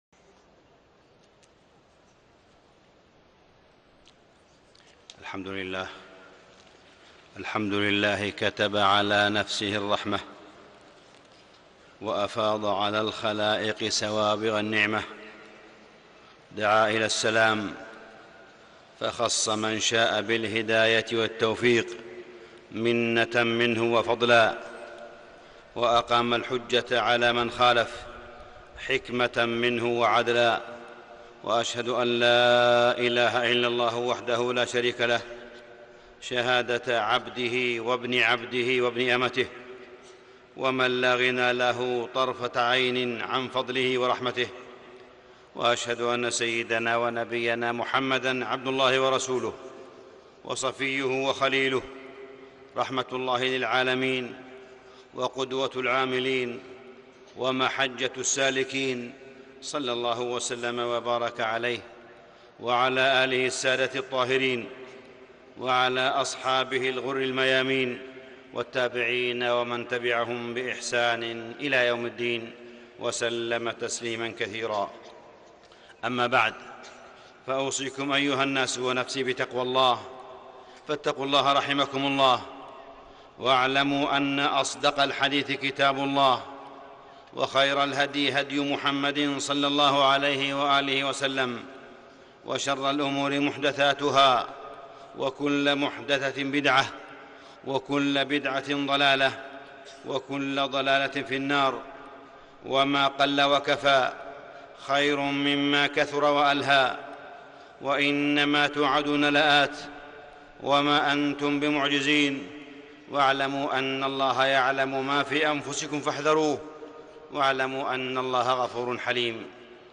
Makkah Jumua Khutbah 8th Feb 2013 - Shaykh Saleh bin Humaid - E M A A N L I B R A R Y .
Speaker: .Shaykh Saleh bin Humaid Language: ARABIC 1.
HD-Makkah-Jumua-Khutbah-8th-Feb-2013-Sheikh-Humaid.mp3